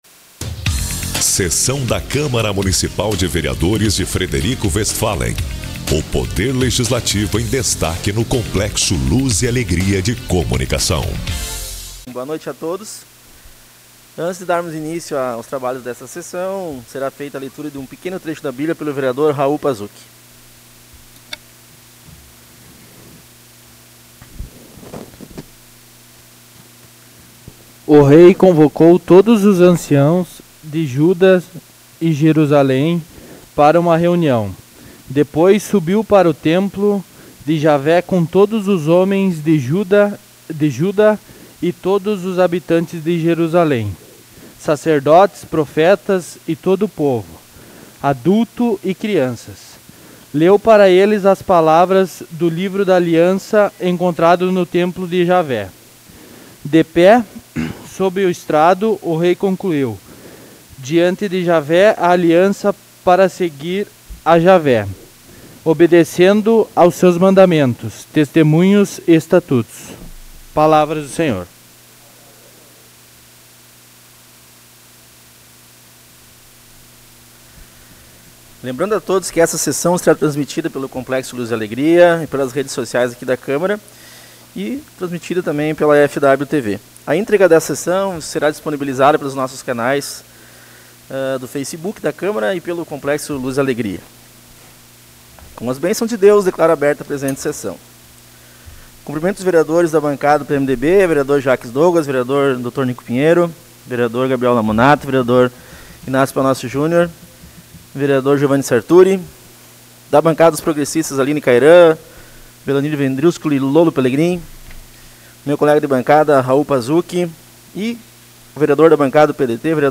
Sessão Ordinária 20 de abril